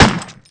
GUN_PF.WAV